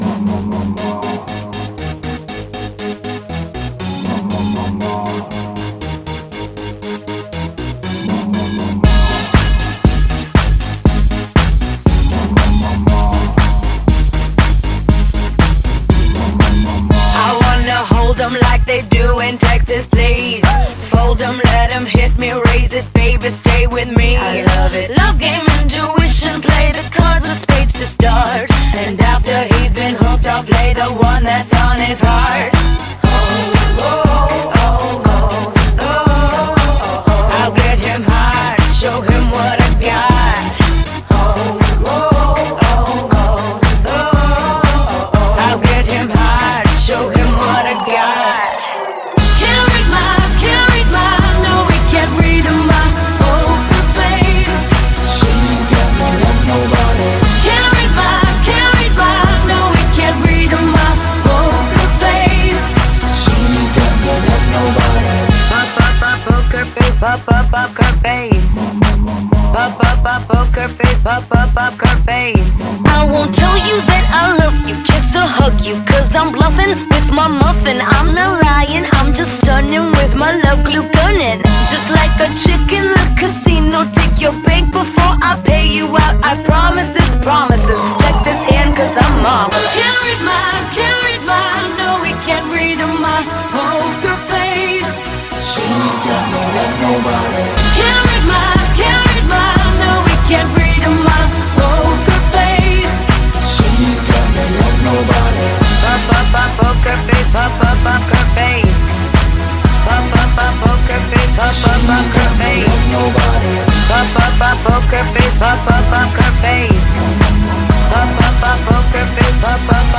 BPM119
MP3 QualityMusic Cut
And yes, I know this is horribly off-sync.